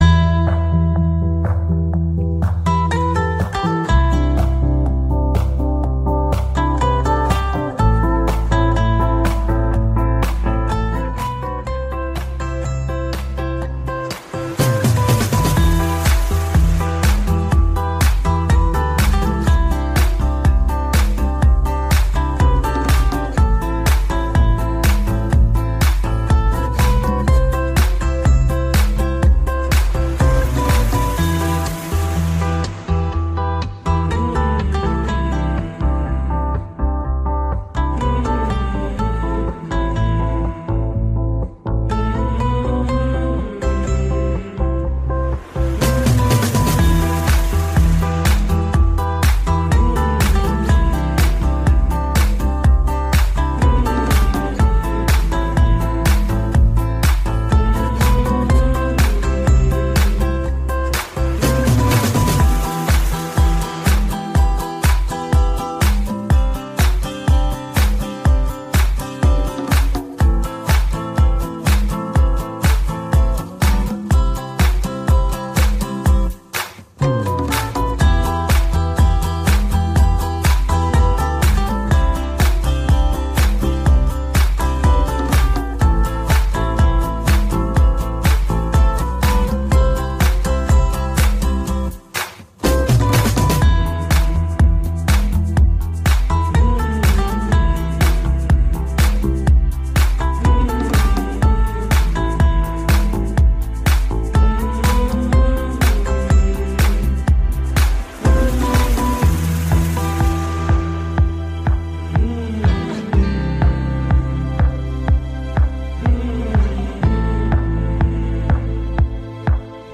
BoardingMusic[2].ogg